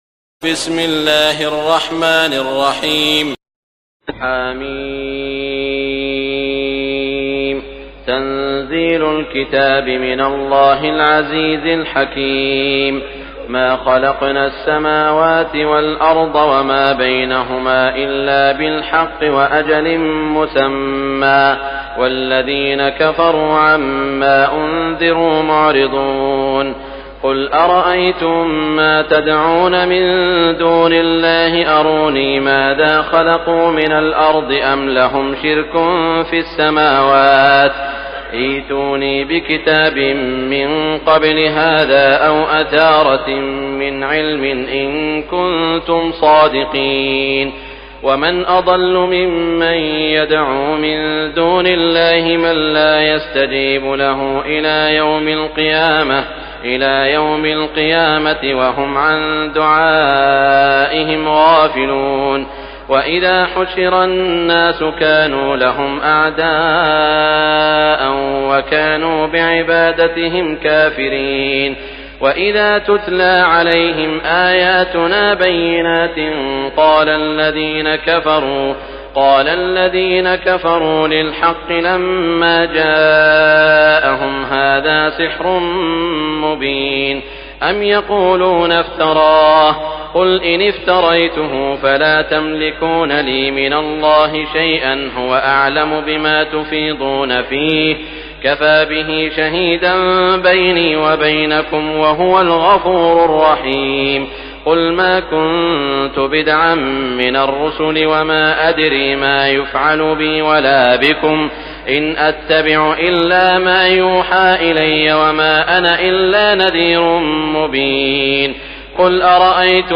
تراويح ليلة 25 رمضان 1418هـ من سور الأحقاف و محمد و الفتح (1-17) Taraweeh 25 st night Ramadan 1418H from Surah Al-Ahqaf and Muhammad and Al-Fath > تراويح الحرم المكي عام 1418 🕋 > التراويح - تلاوات الحرمين